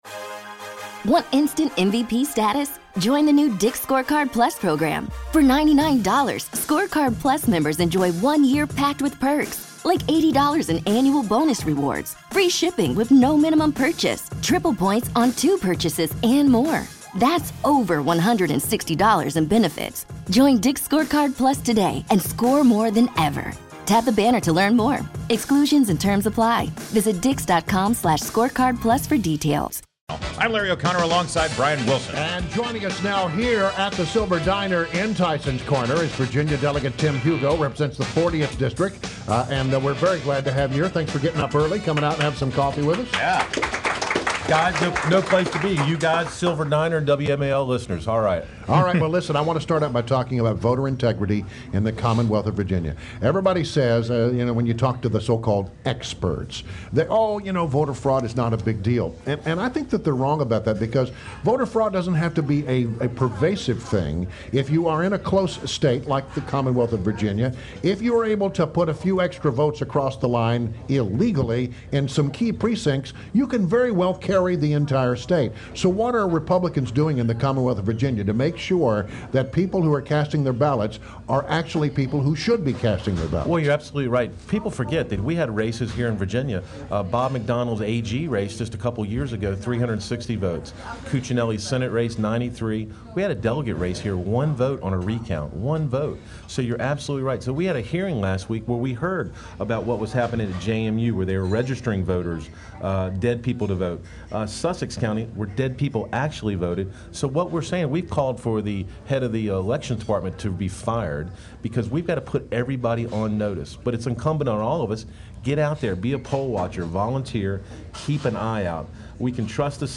WMAL Interview - VA DEL. TIM HUGO - 10.21.16